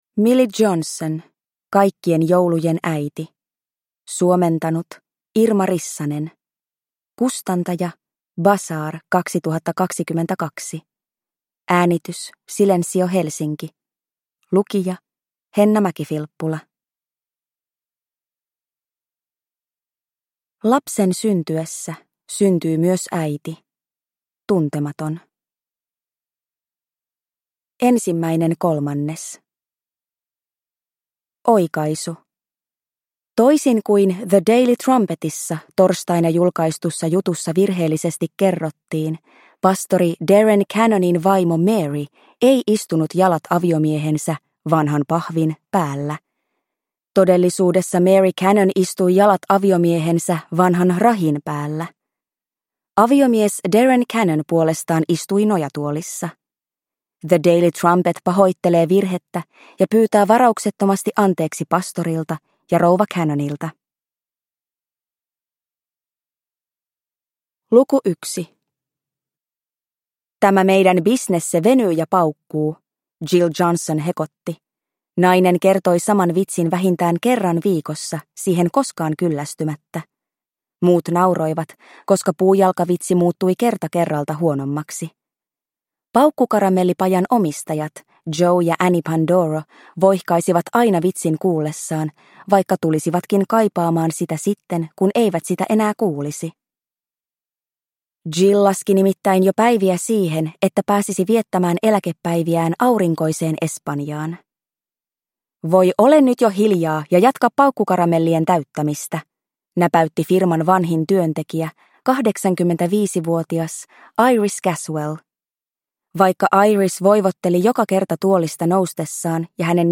Kaikkien joulujen äiti – Ljudbok – Laddas ner